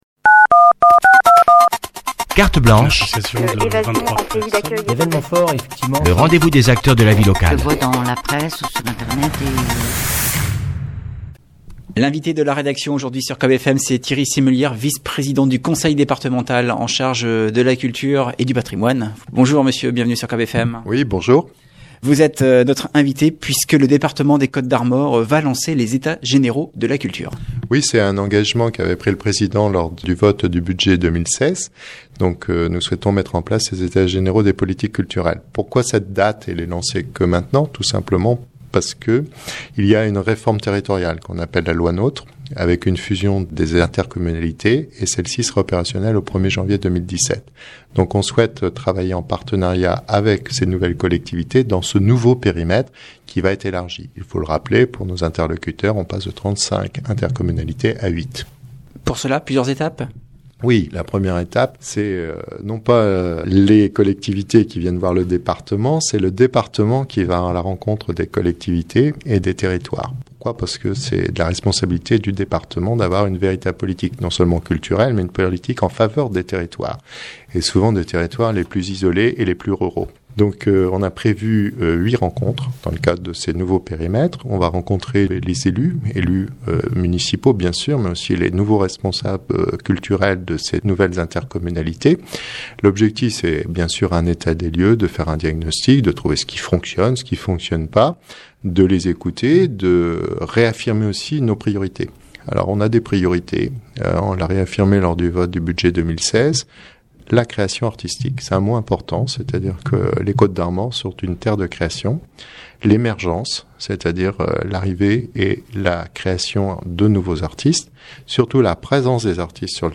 Entretien avec Thierry Simelière, vice-président du Conseil départemental en charge de la culture et du patrimoine